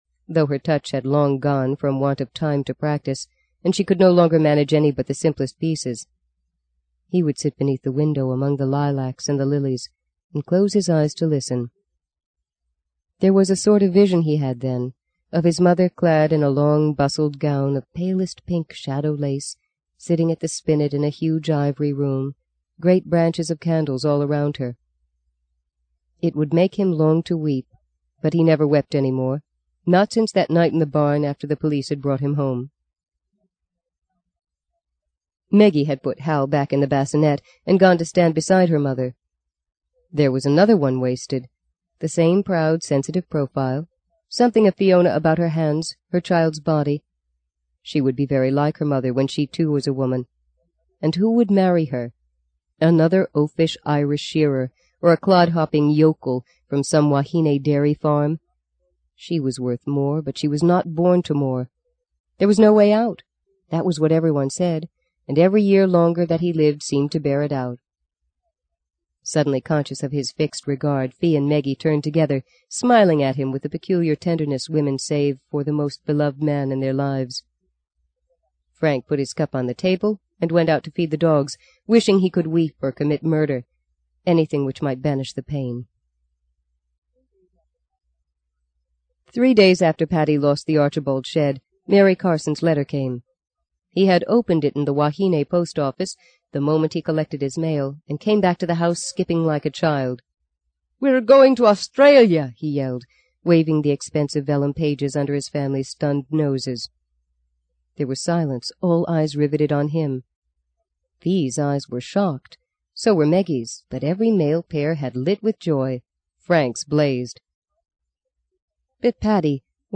在线英语听力室【荆棘鸟】第三章 12的听力文件下载,荆棘鸟—双语有声读物—听力教程—英语听力—在线英语听力室